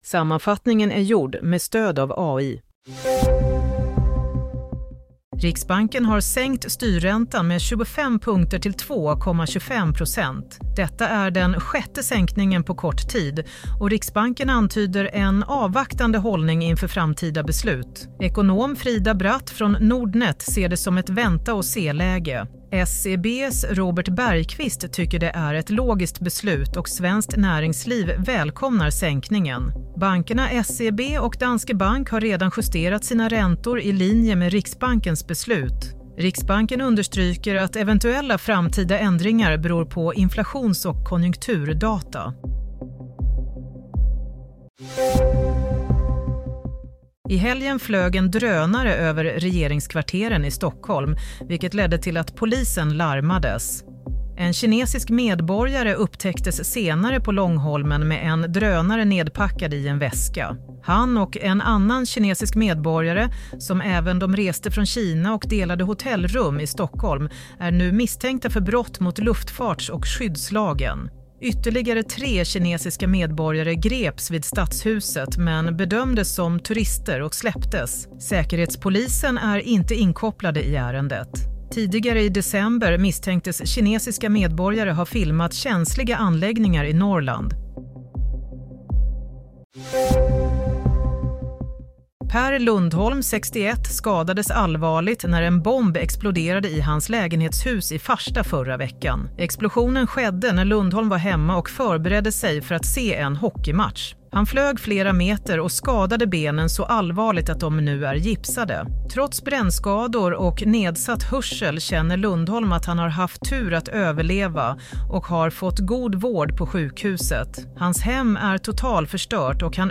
Nyhetssammanfattning – 29 januari 16.00